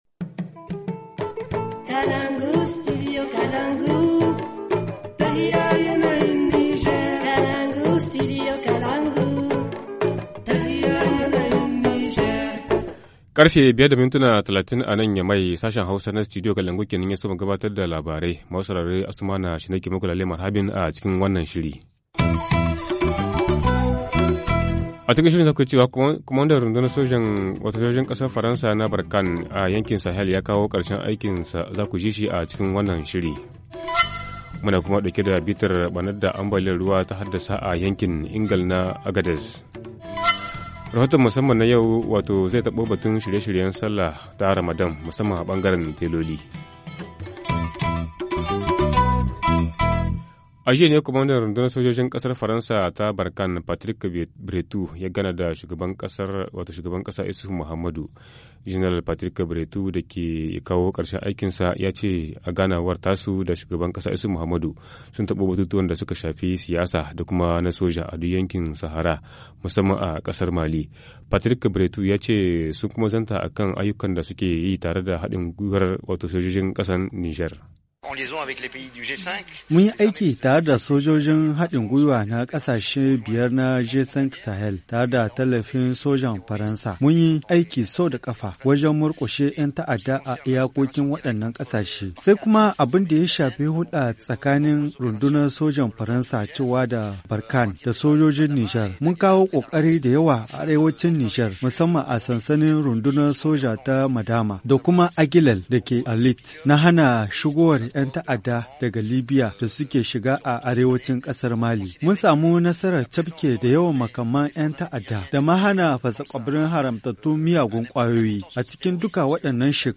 Journal du 30 juin 2016 - Studio Kalangou - Au rythme du Niger